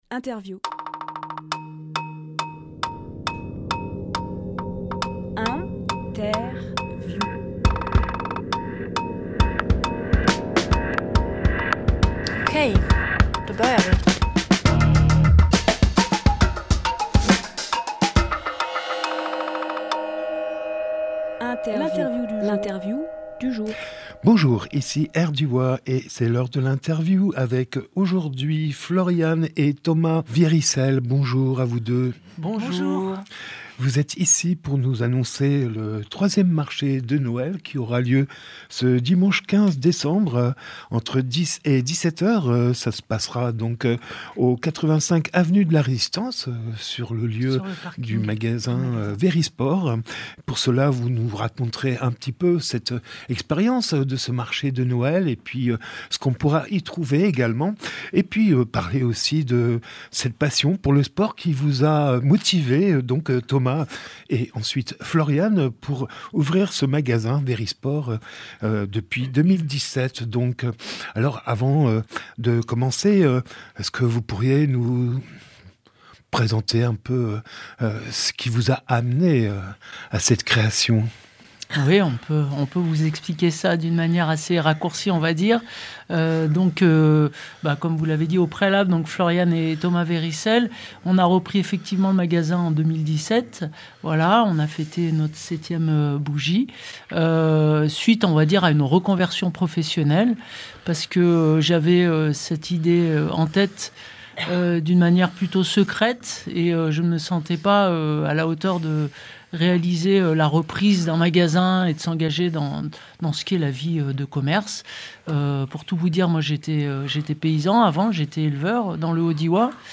Emission - Interview Marché de Noël à Verisport Publié le 19 novembre 2024 Partager sur…
lieu : studio Rdwa